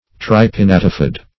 Tripinnatifid \Tri`pin*nat"i*fid\, a. [Pref. tri- + pinnatifid.]